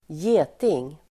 Ladda ner uttalet
geting substantiv, wasp Uttal: [²j'e:ting]